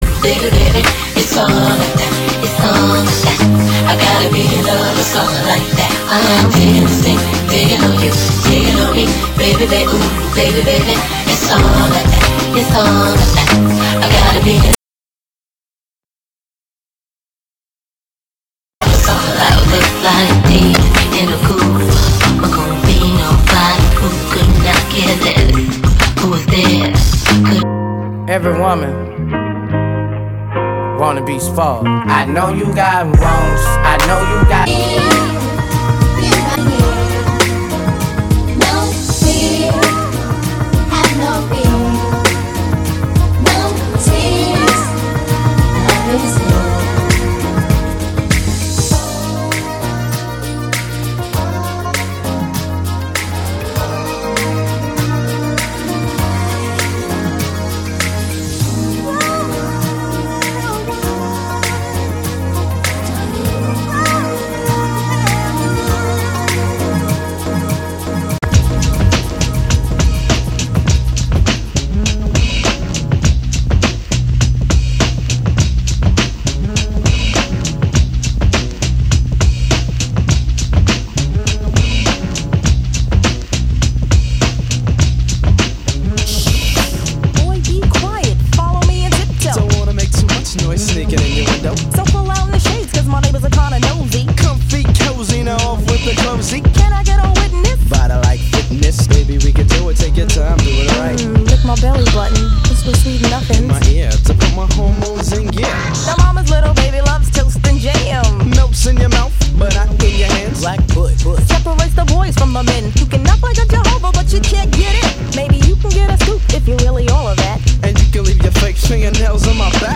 Instrumentals